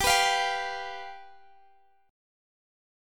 G7 Chord (page 4)
Listen to G7 strummed